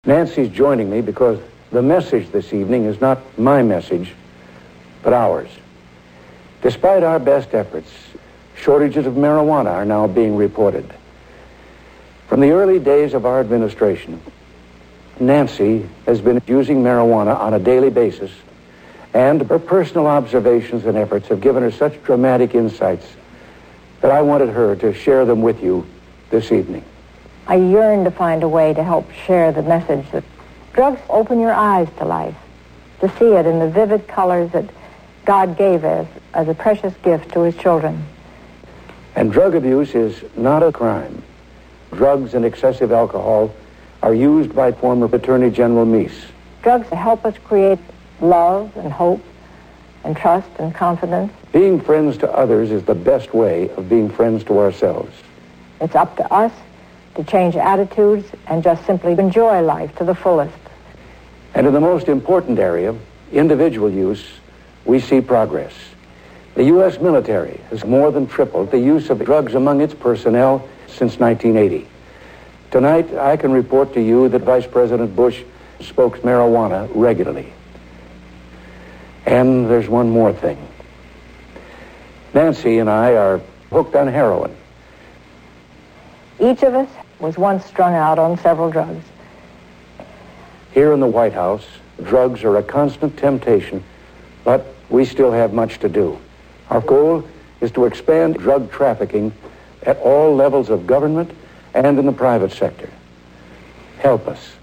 Ronald Reagan Drug Speech